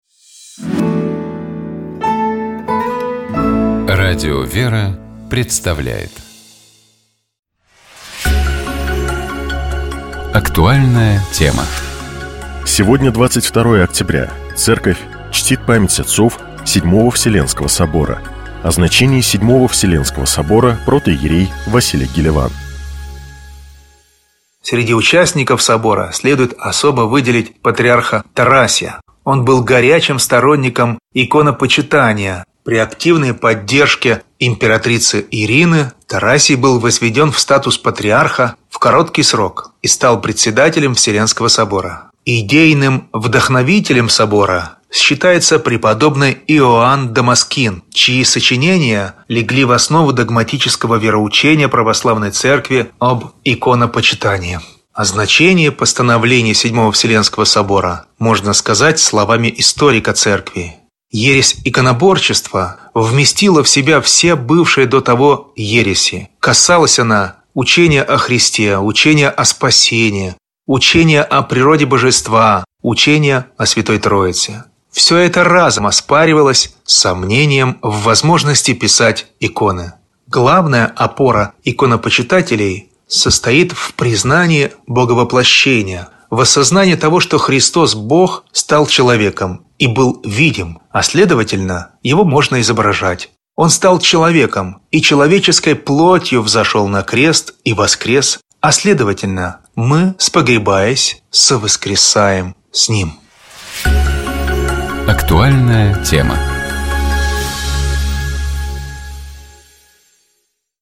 протоиерей